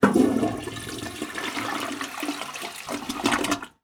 Toilet Flush Short Sound
household